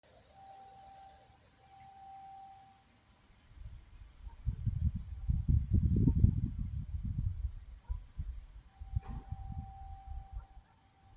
Rezonancia na chladiči
Vzniká na úzkych otvoroch medzi rebrami chladiča, keď cez ne prefukuje vietor. Výsledkom je stabilný tón („pískanie“) s takmer konštantnou výškou. Ukážka pískania tu.